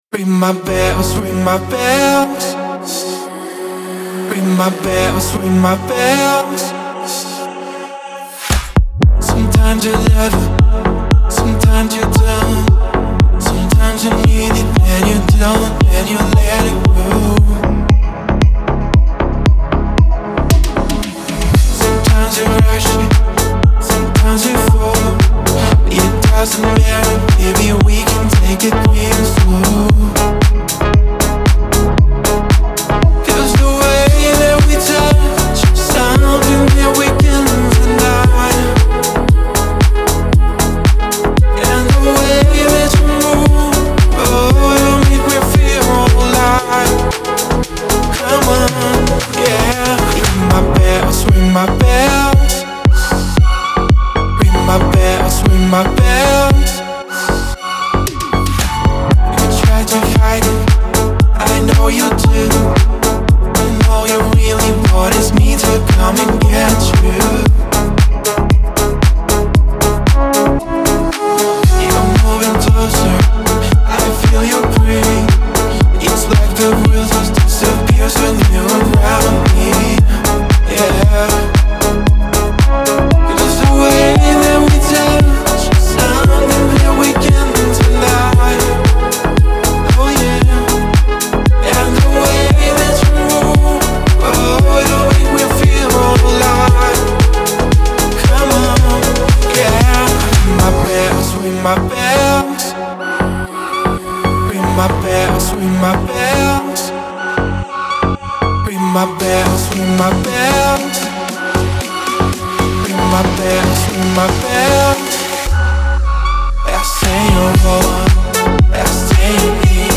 диско
эстрада , дуэт , dance